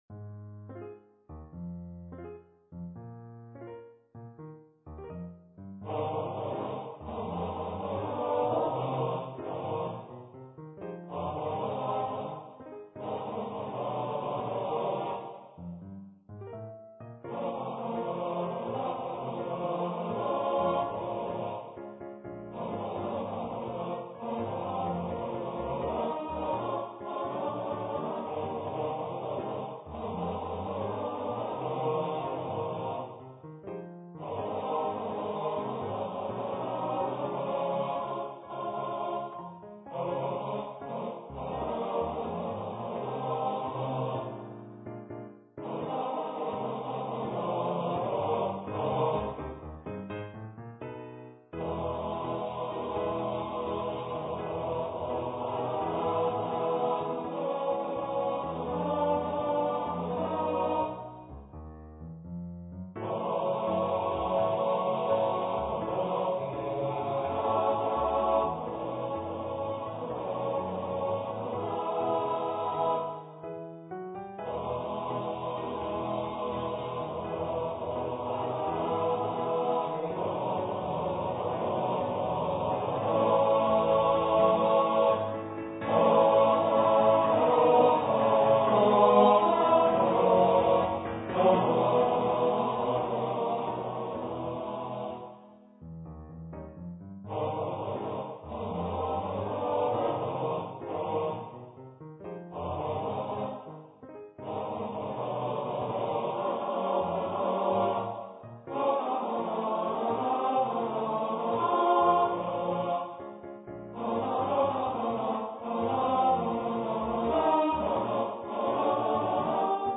for TTBB choir and Piano
An original light-hearted song of celebration.
Choir - Male voices